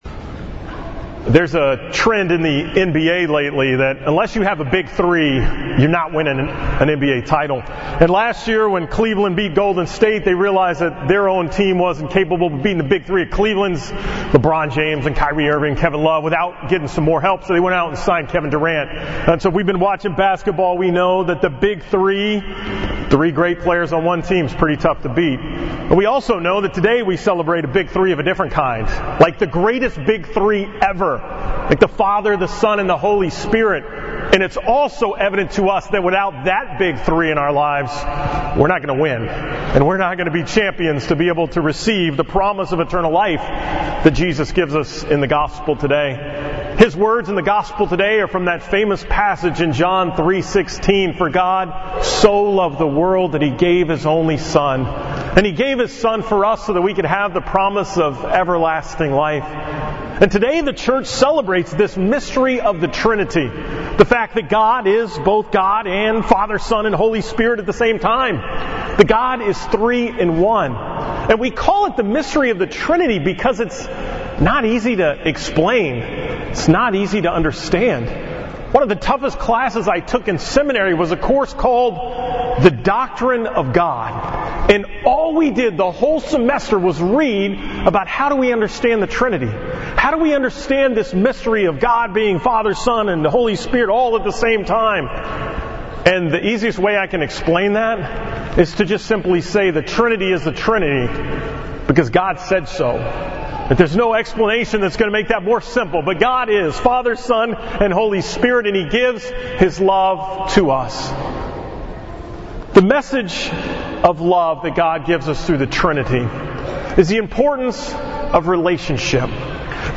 From the 11 am Mass at St. Helen's on Sunday, June 11, 2017 on the Most Holy Trinity